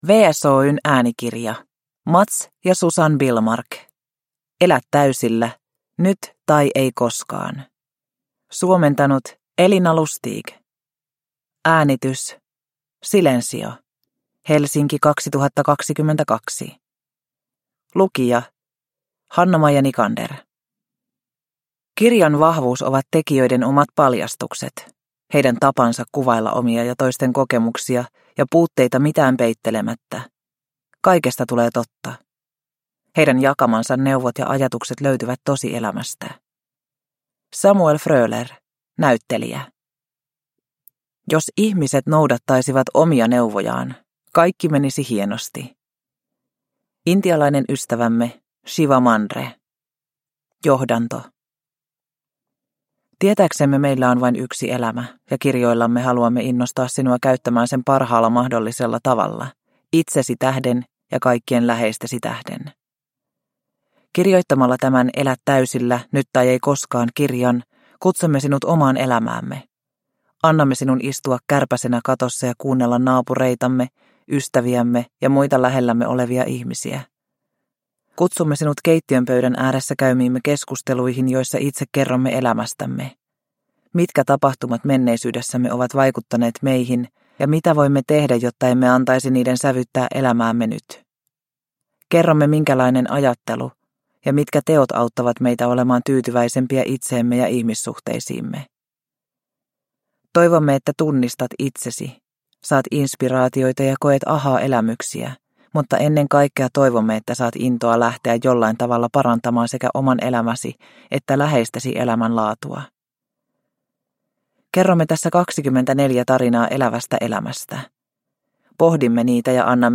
Elä täysillä - nyt tai ei koskaan – Ljudbok – Laddas ner